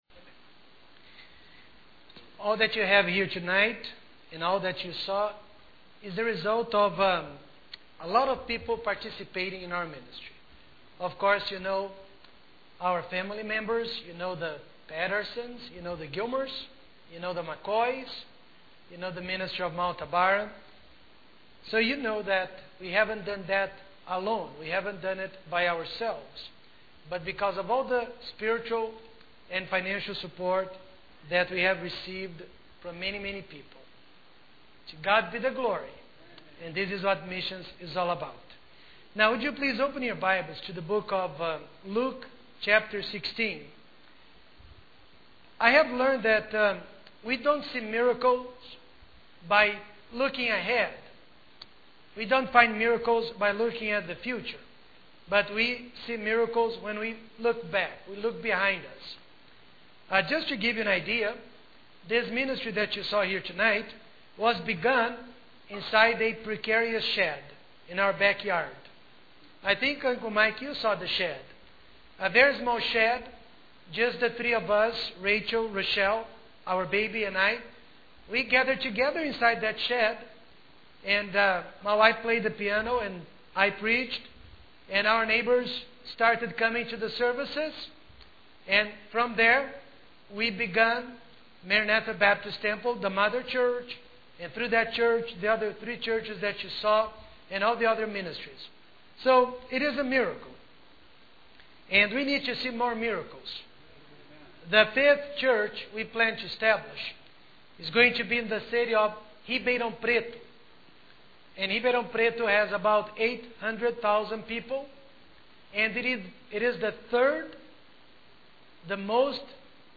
Sermon Audio - Media of Worth Baptist Church